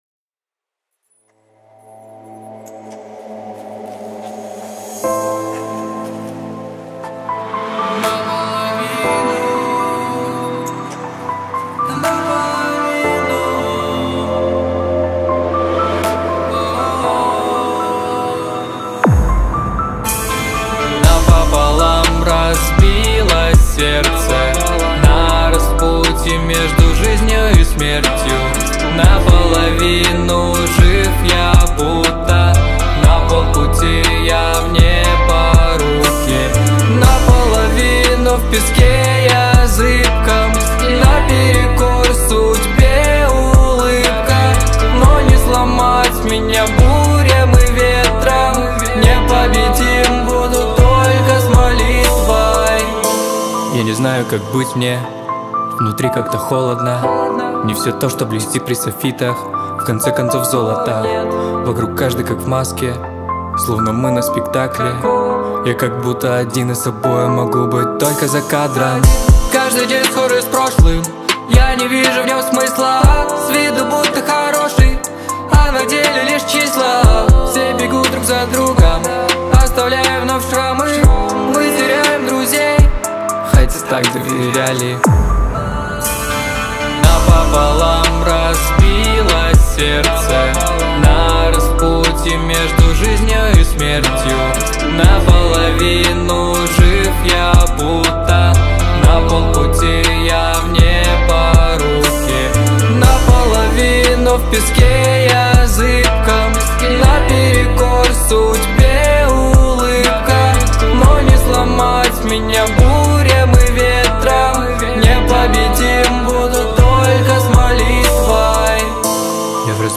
276 просмотров 476 прослушиваний 33 скачивания BPM: 120